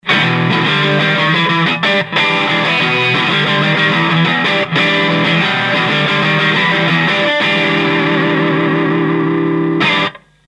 ３台目にして遂に一発で音が出ました。